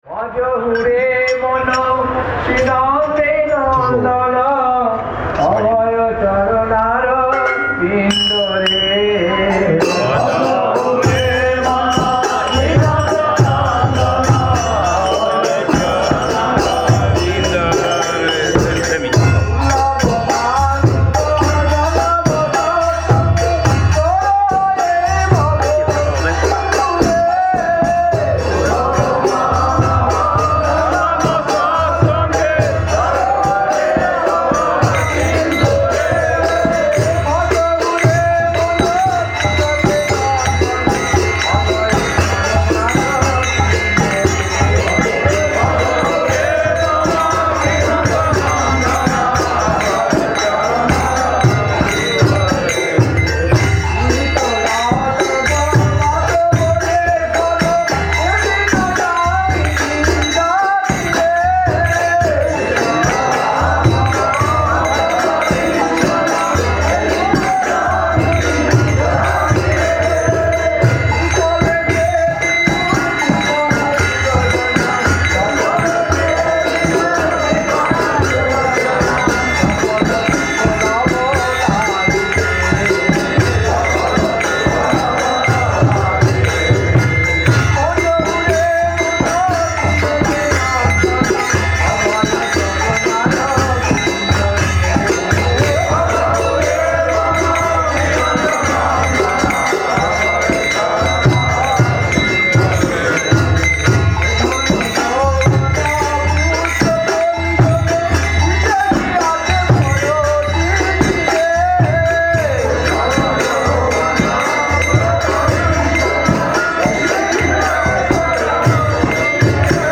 Место: ШЧСМатх Пури
Тэги: Киртан